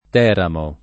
Teramo [ t $ ramo ]